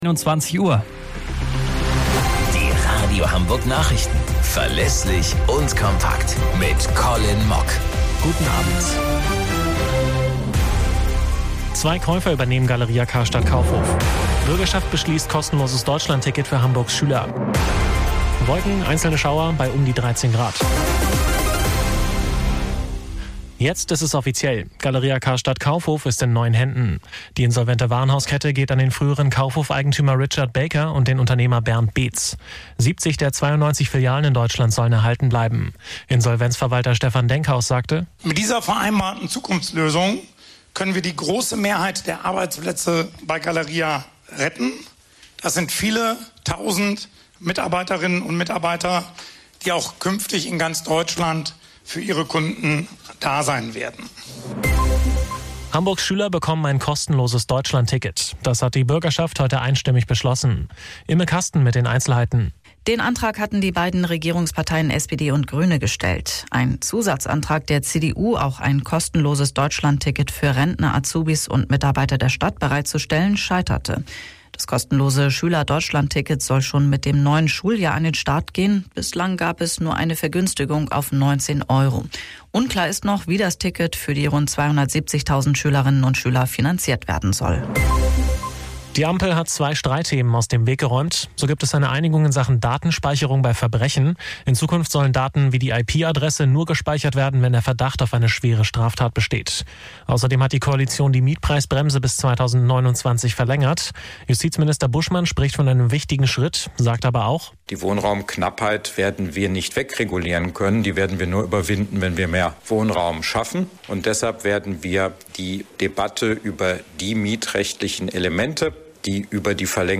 Radio Hamburg Nachrichten vom 11.04.2024 um 04 Uhr - 11.04.2024